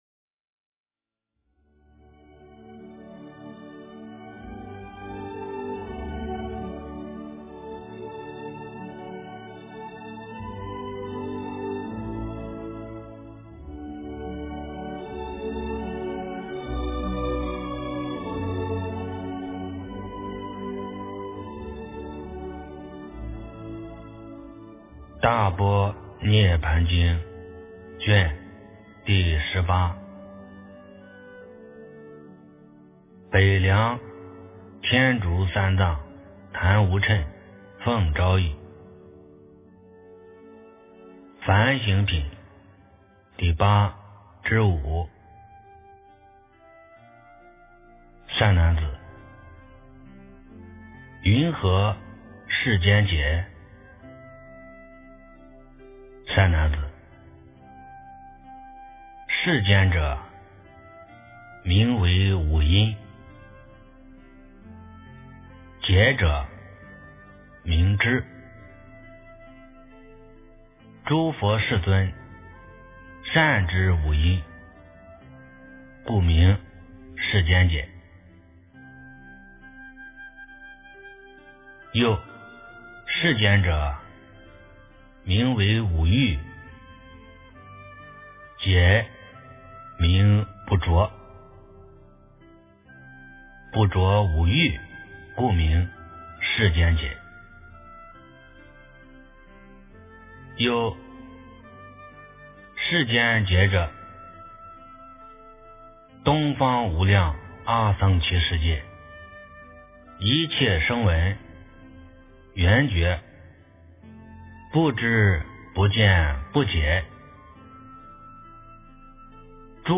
大般涅槃经18 - 诵经 - 云佛论坛